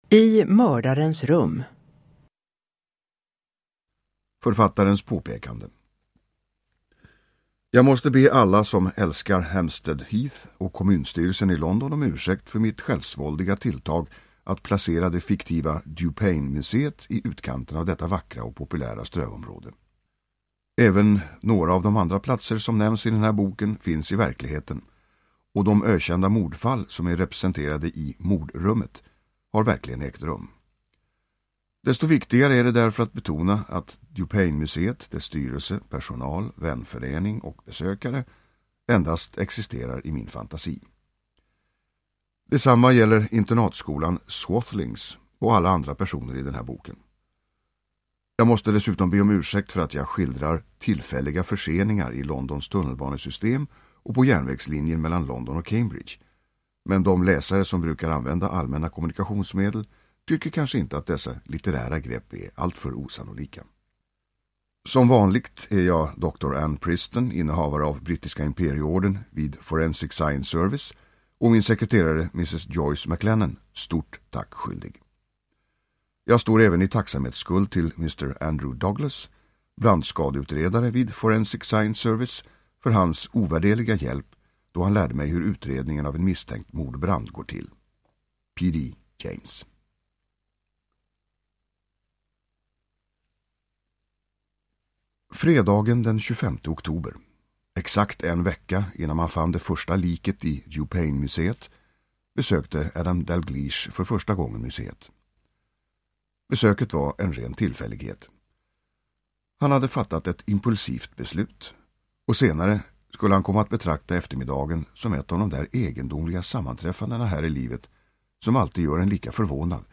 I mördarens rum – Ljudbok – Laddas ner
Uppläsare: Tomas Bolme